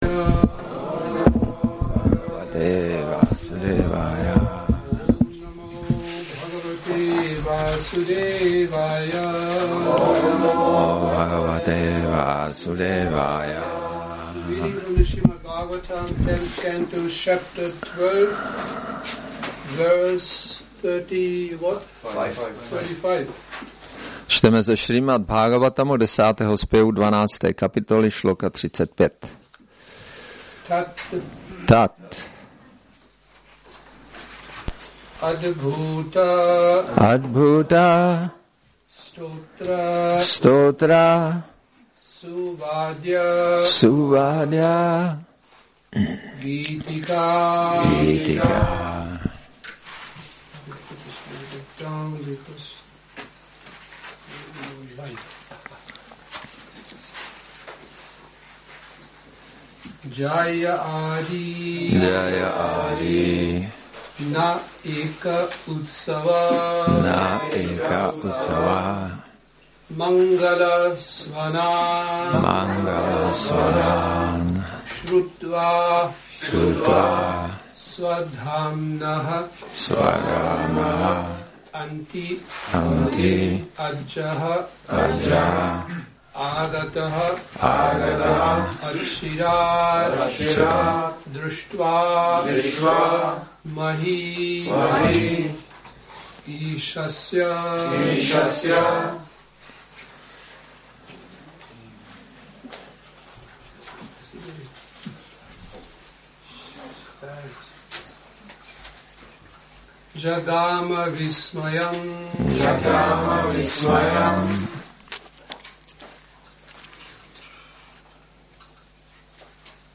Přednáška SB-10.12.35 – Šrí Šrí Nitái Navadvípačandra mandir